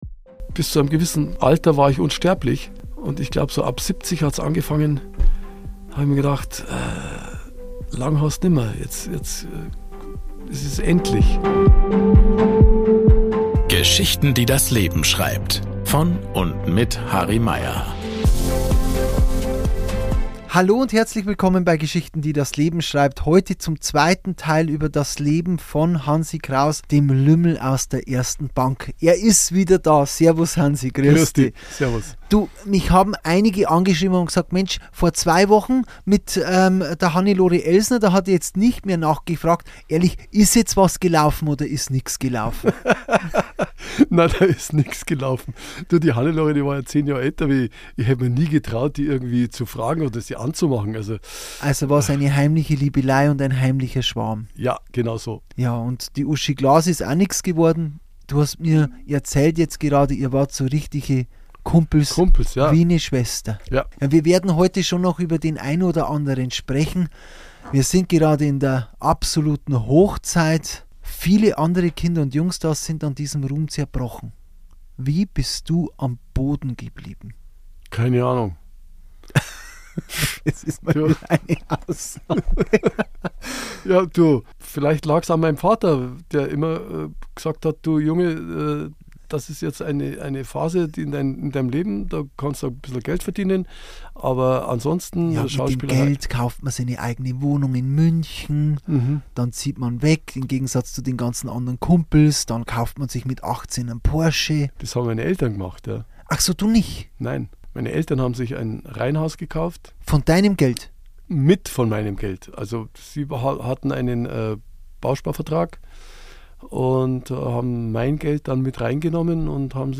Wie er mit der Liebe seines Lebens eine Familie gründete, warum er Erzieher wurde – und wie er eine Nahtoderfahrung durchlebte. Ein inspirierendes Gespräch über das Leben nach dem Erfolg, neue Chancen und innere Stärke.